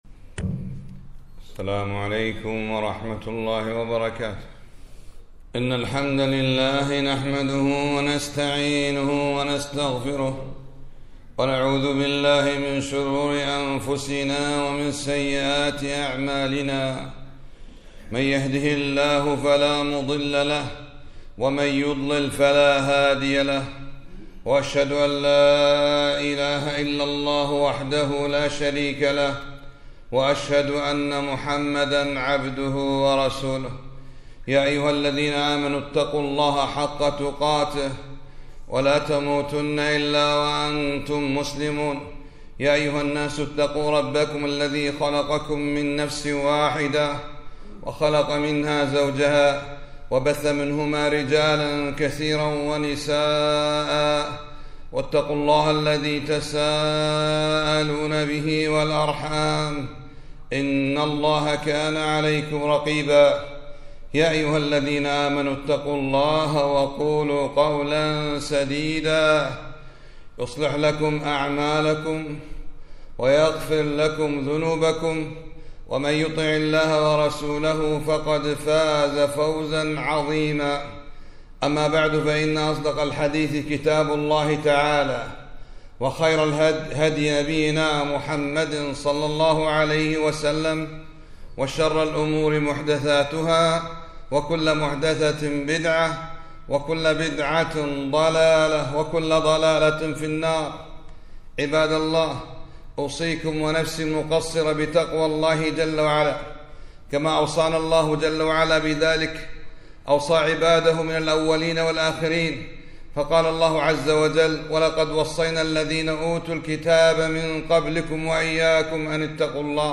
خطبة - من هو الكريم؟